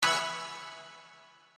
beep_01.mp3